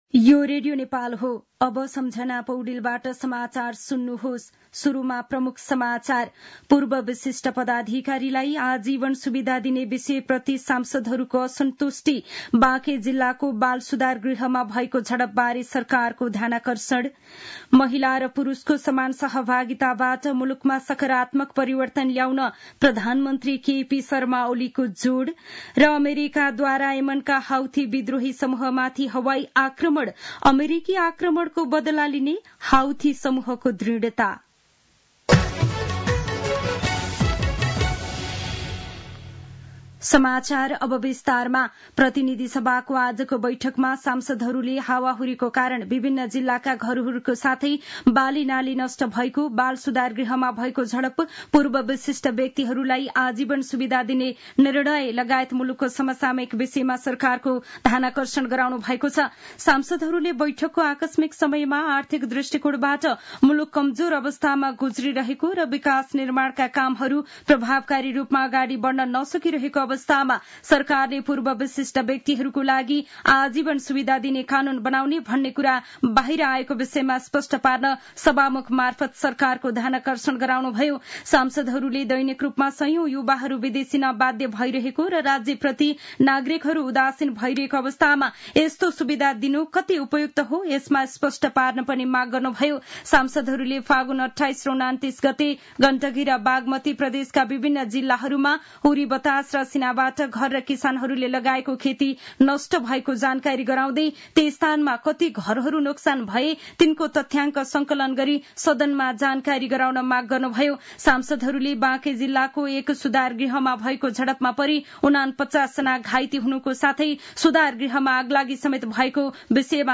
दिउँसो ३ बजेको नेपाली समाचार : ३ चैत , २०८१
3-pm-news-1-4.mp3